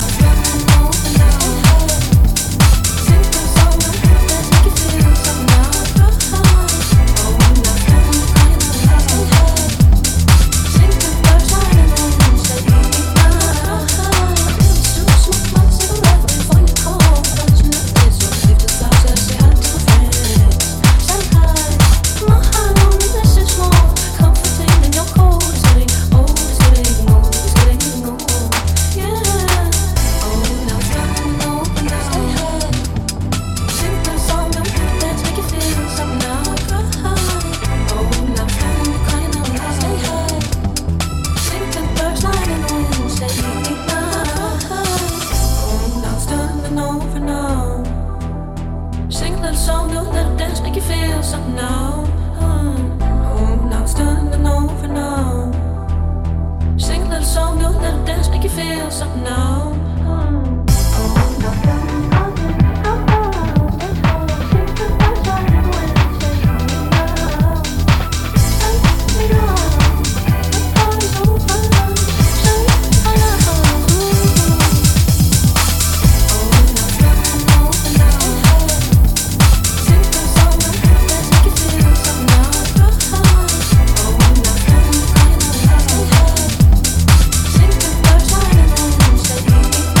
four-to-the-floor club music